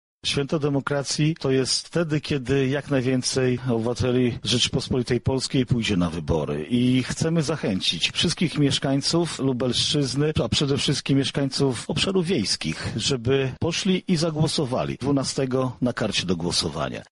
Chcemy, aby niedzielne wybory były prawdziwym świętem demokracji – mówi Marszałek Województwa Lubelskiego Jarosław Stawiarski .